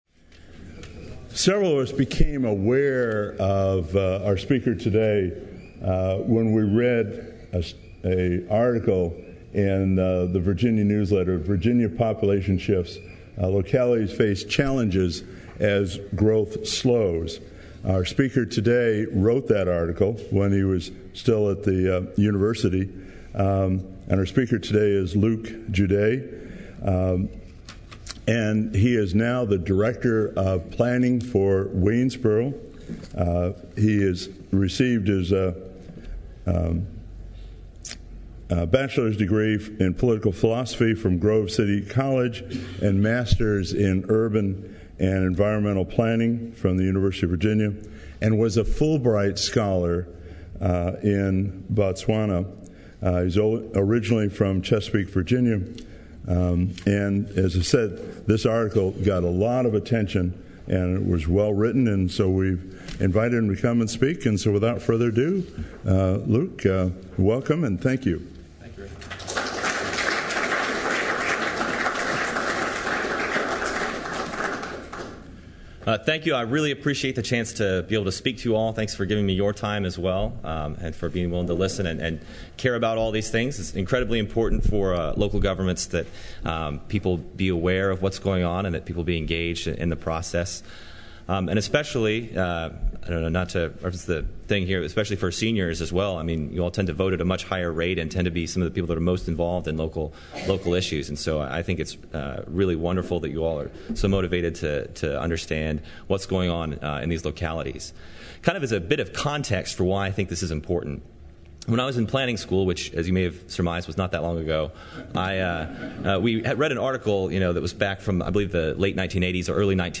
The meeting was held at the Senior Center in Charlottesville. Following the presentation, questions were taken from the audience.